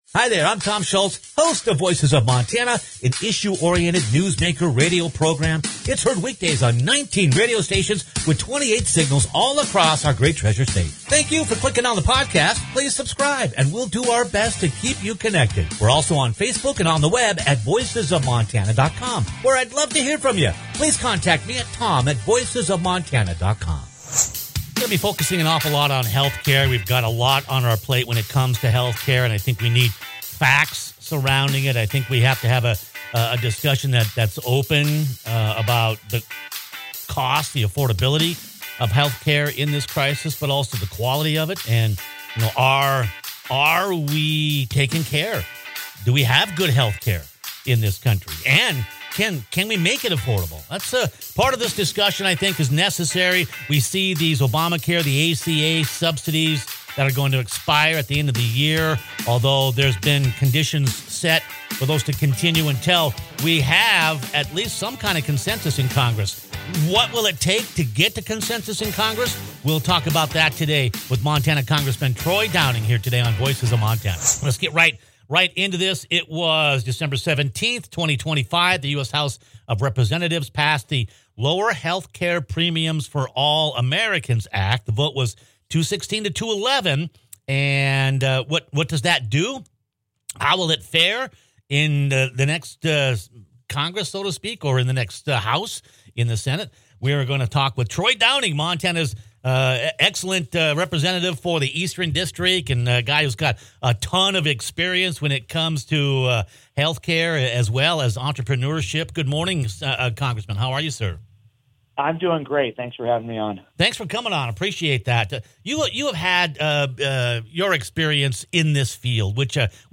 The U.S. House has recently passed a Healthcare funding measure – it’s one that will get the ball rolling as Congress has yet to tackle the impending deadline for the end of the Covid-19 ACA insurance subsidies. Montana Congressman Troy Downing joins us to discuss what’s in the House package, and what’s ahead as America